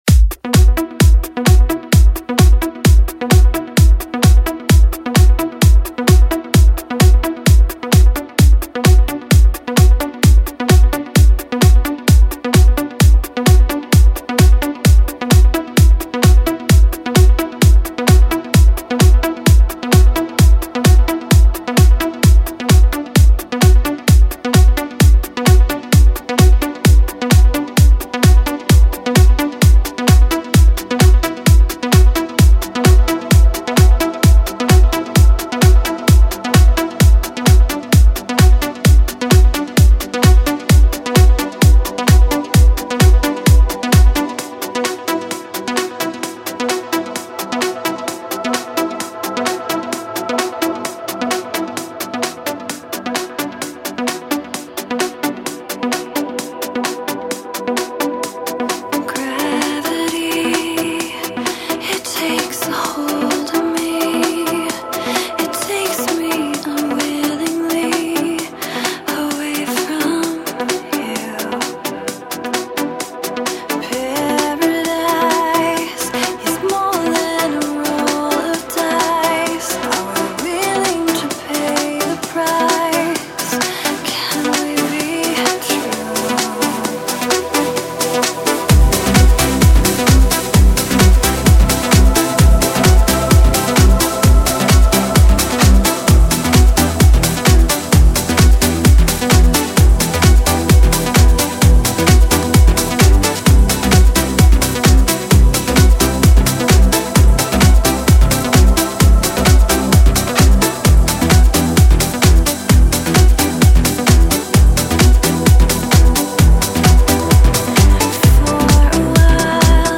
موسیقی ترنس